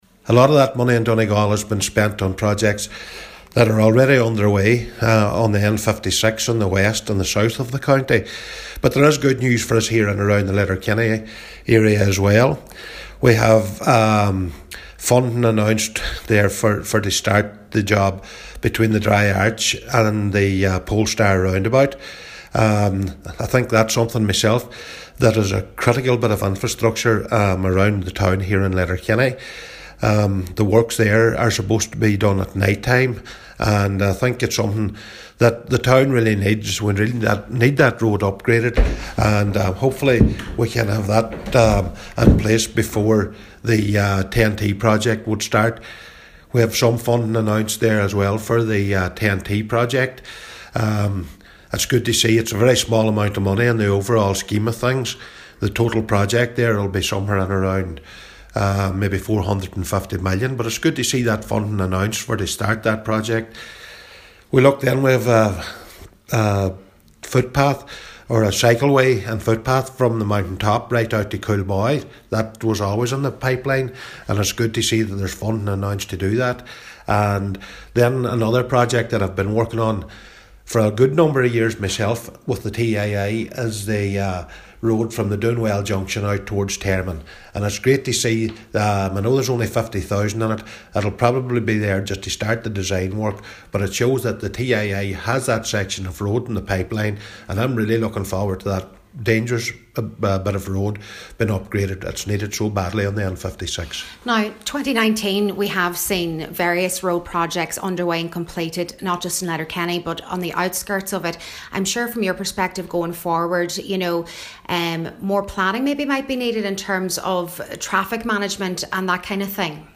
While acknowledging the impact this had on traders, Cllr Michael McBride says we need to be looking at the bigger picture: